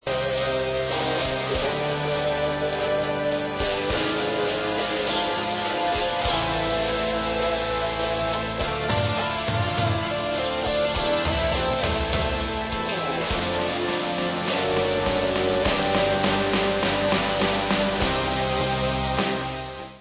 sledovat novinky v oddělení Pop/Symphonic